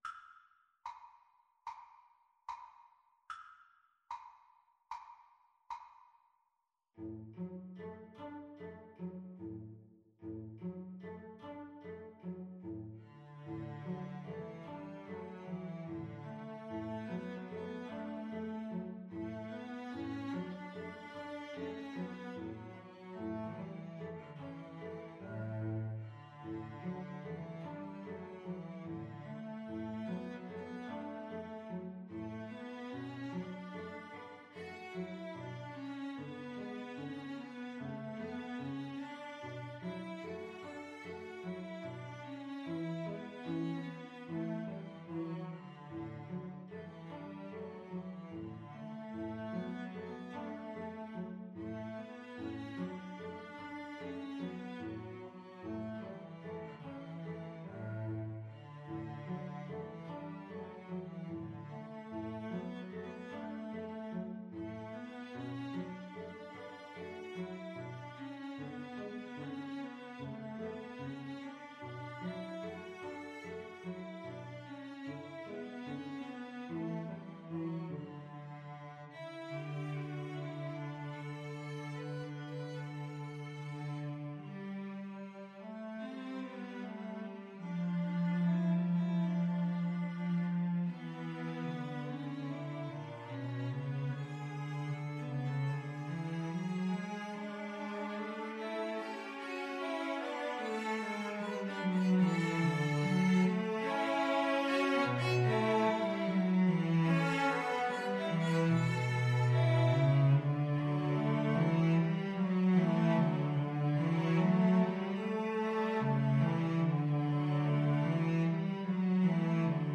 Free Sheet music for Cello Trio
Andantino quasi allegretto ( = 74) (View more music marked Andantino)
D major (Sounding Pitch) (View more D major Music for Cello Trio )
Classical (View more Classical Cello Trio Music)